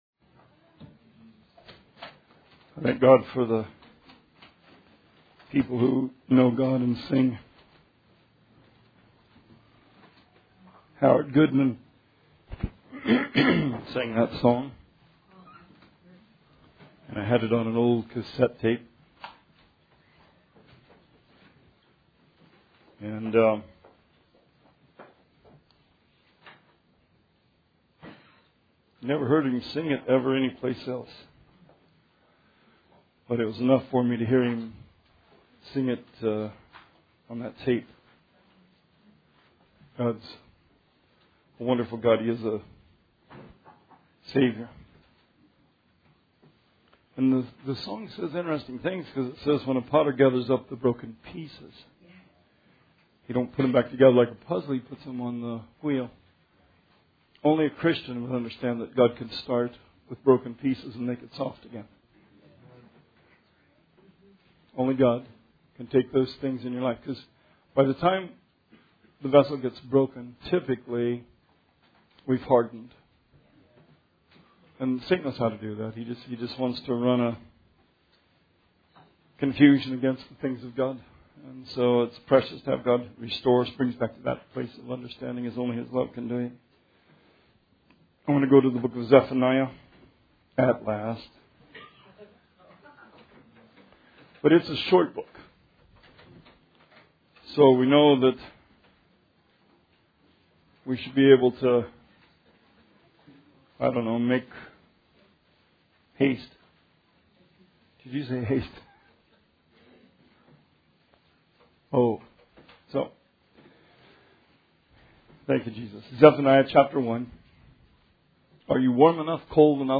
Sermon 11/19/17